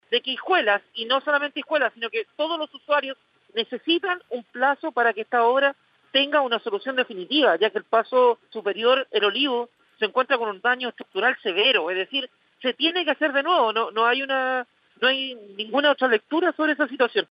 En conversaciones con Radio Bío Bío, el jefe comunal señaló que hasta la fecha la situación no ha sido solucionada del todo.
cu-puente-hijuelas-alcalde-hijuelas.mp3